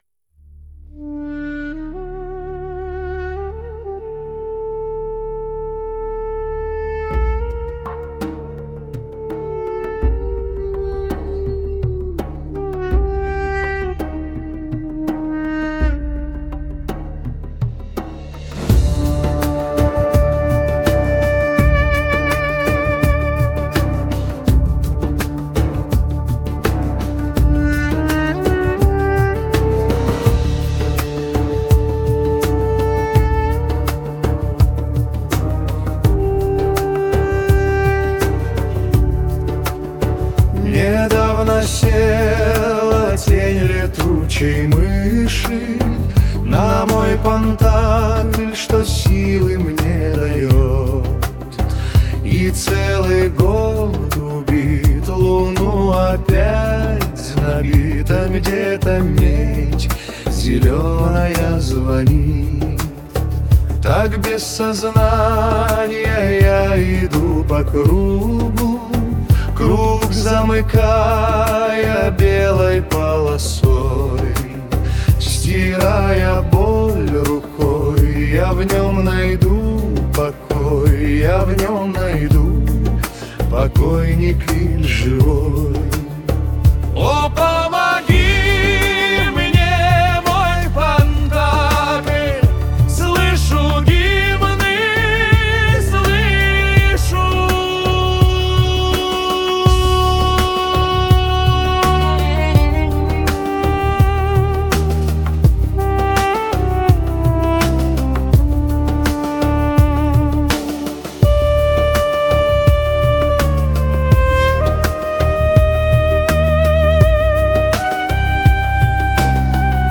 Один - мультиинструменталист, другой - бас и ударные.
Фолк